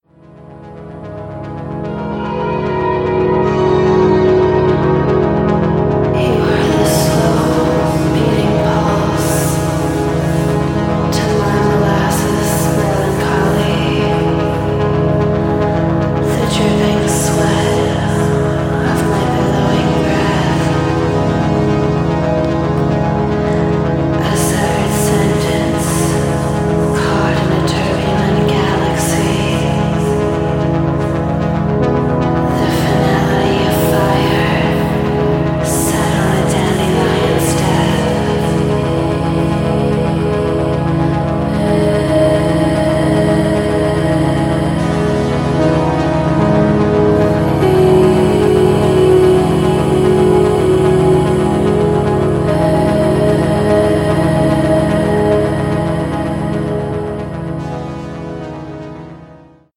gorgeous, sensual electro and ambient music
House Techno